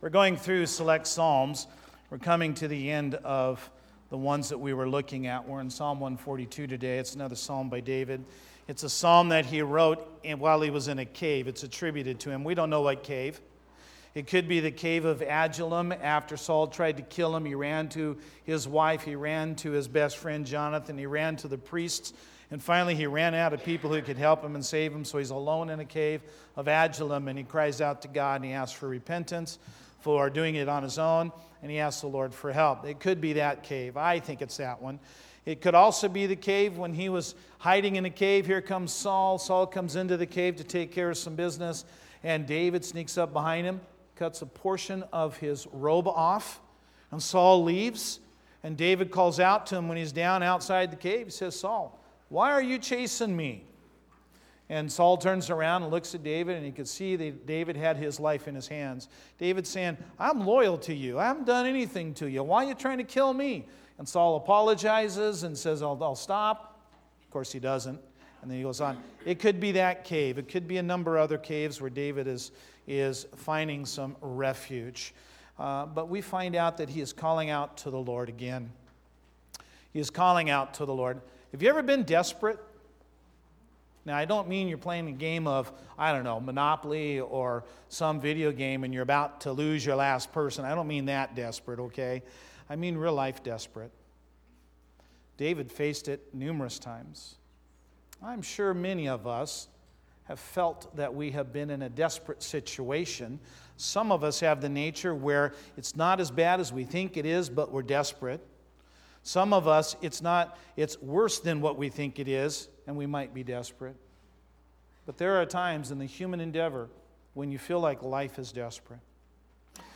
5-5-19-Sermon.mp3